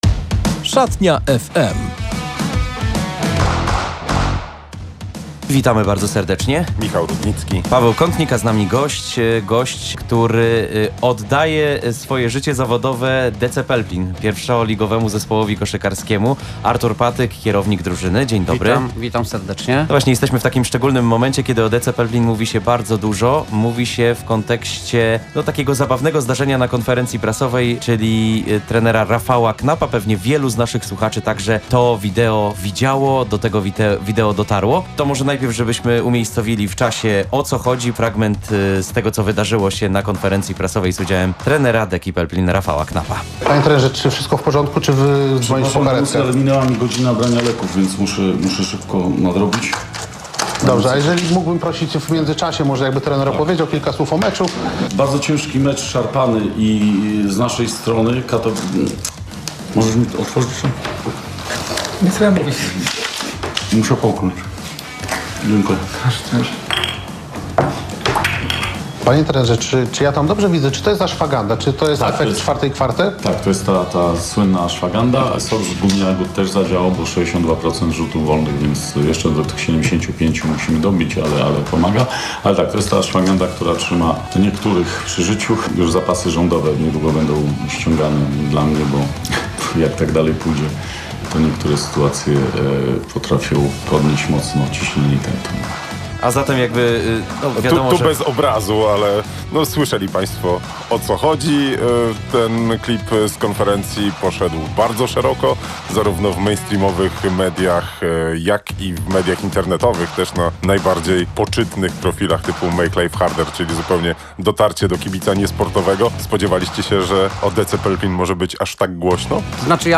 rozmawiamy